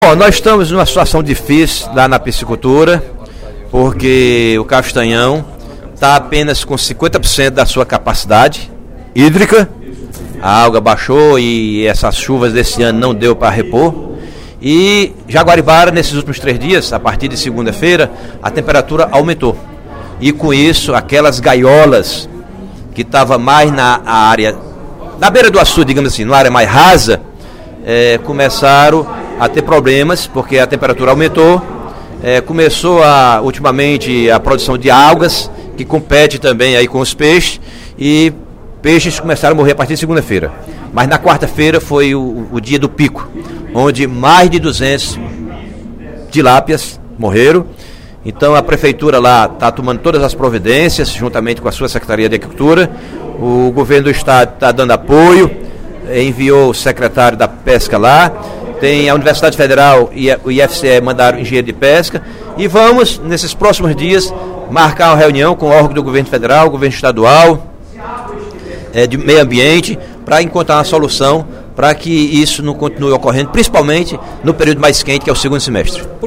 O deputado Antônio Granja (PSB) chamou atenção, no primeiro expediente da sessão desta quinta-feira (06/06), para a situação da piscicultura no município de Jaguaribara. Segundo ele, a criação de tilápias consiste na principal atividade econômica do município, e começou a ser afetada, nas últimas semanas, pela redução do nível de água do açude Castanhão.